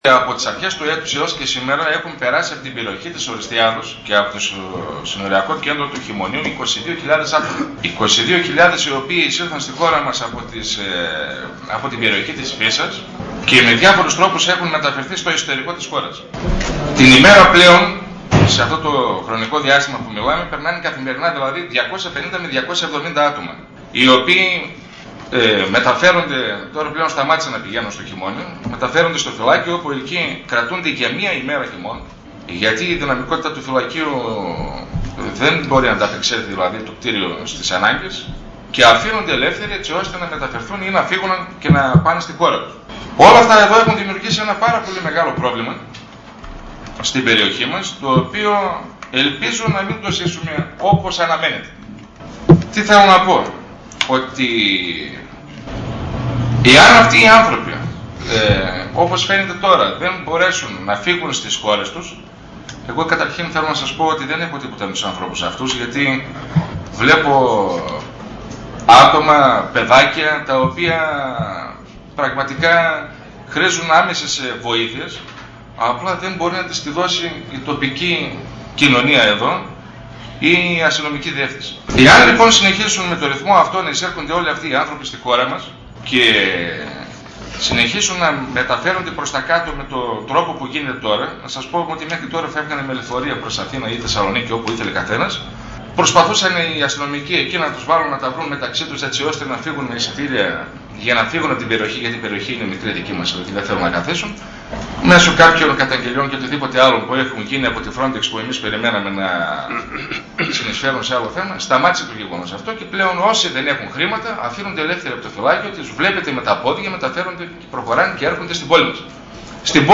Εισήγηση Αντιδημάρχου Ουρουμίδη για το θέμα της Λαθρομετανάστευσης – Δημ. Συμβ. Ορ/δας 14.09.2010